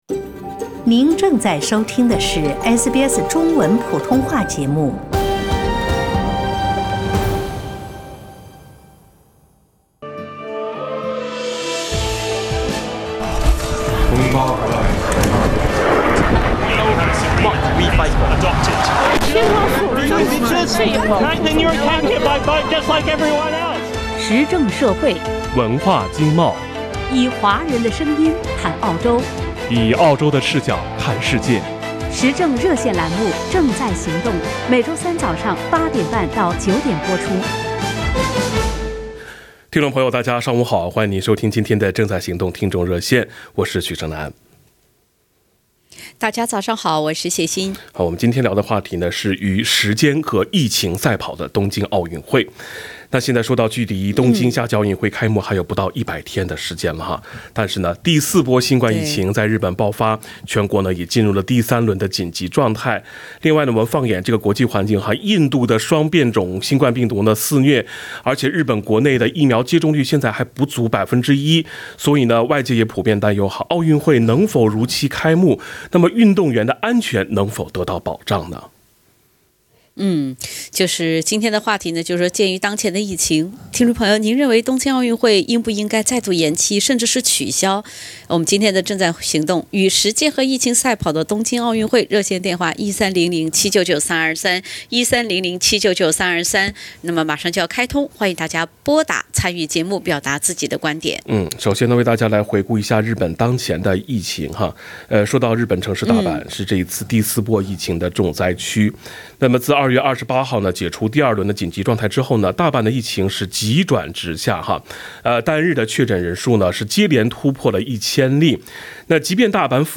鉴于当前疫情，您认为东京奥运会应再度延期甚至取消吗？(点击封面图片，收听热线录音）